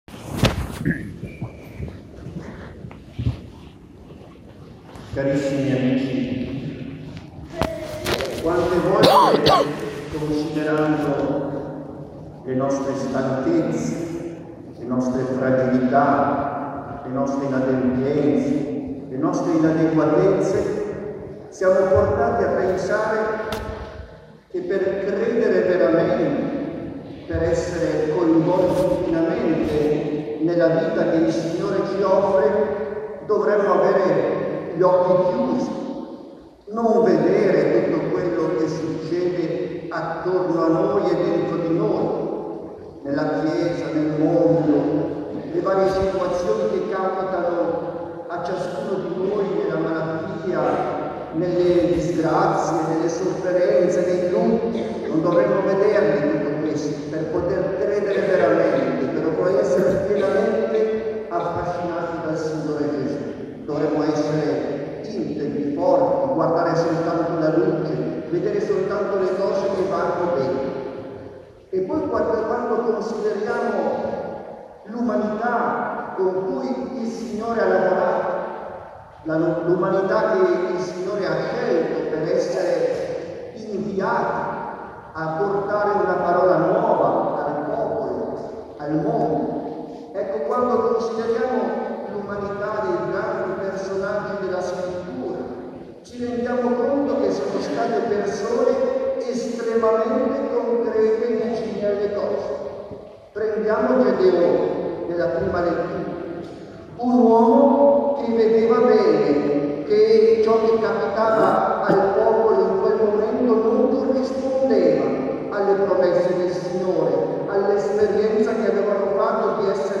20 agosto – Omelia in occasione della liturgia penitenziale a Lourdes (MP3)